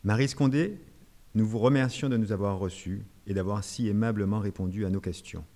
Une lecture à voix haute enregistrée le 21 décembre 2018.